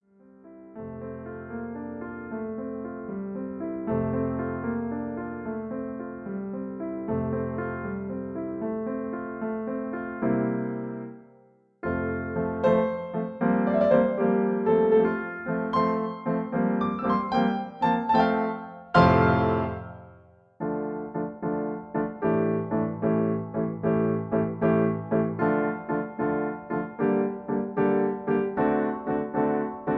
MP3 piano accompaniment
in F major